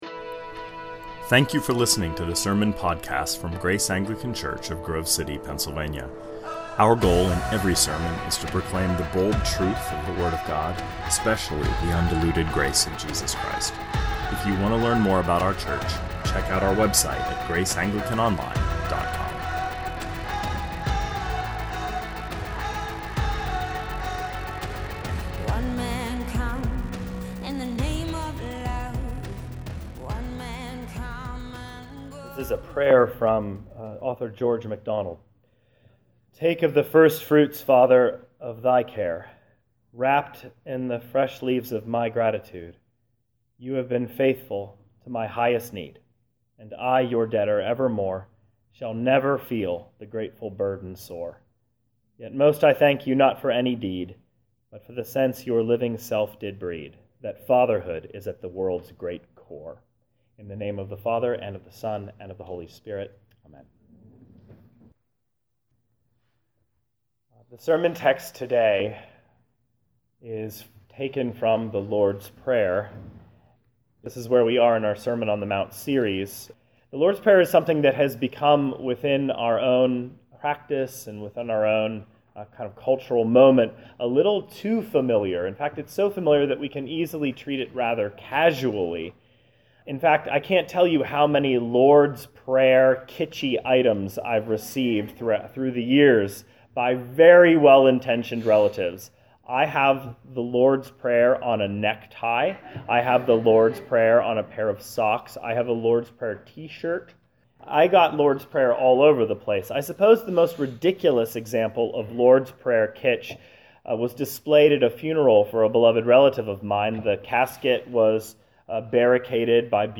2018 Sermons Heaven Lands in Broken Glass -Matthew 6 Play Episode Pause Episode Mute/Unmute Episode Rewind 10 Seconds 1x Fast Forward 30 seconds 00:00 / 26:27 Subscribe Share RSS Feed Share Link Embed